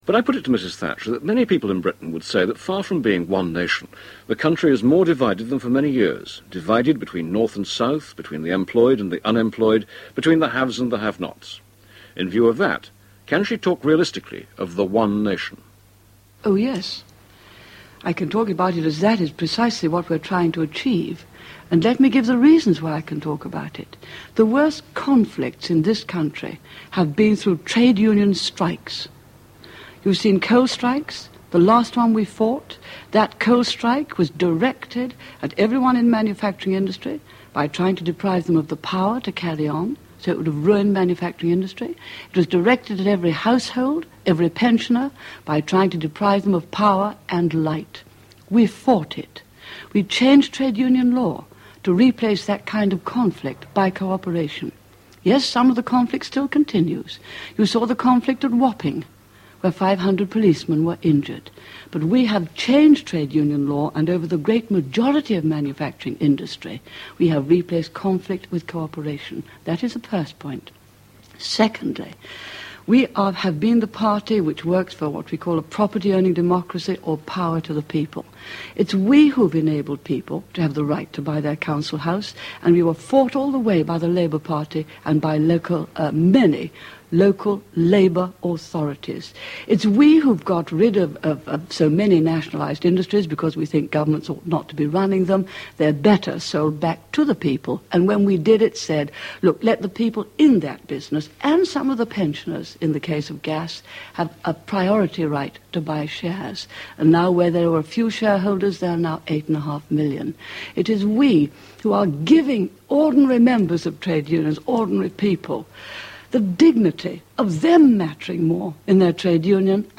Margaret Thatcher giving a pre-election interview to Gordon Clough back in 1987. Taken from The World this Weekend on Radio 4 on 31 May of that year.